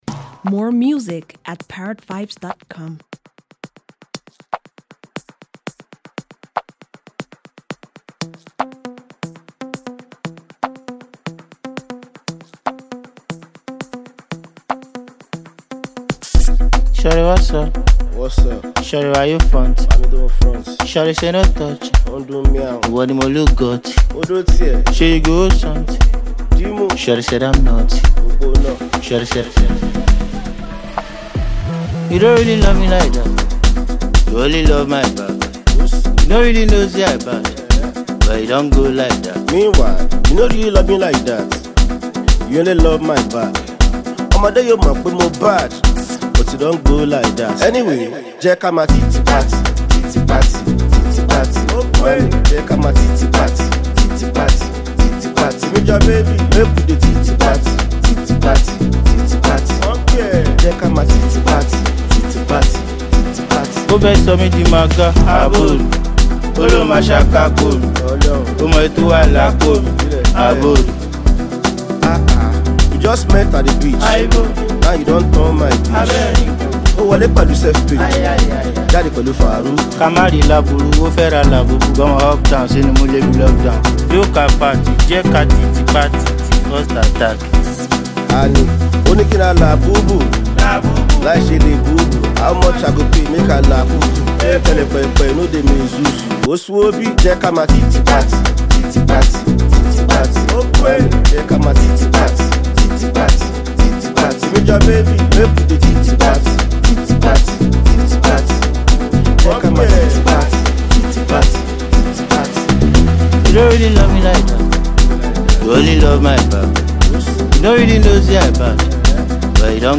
Preeminent Nigerian indigenous musical artist and performer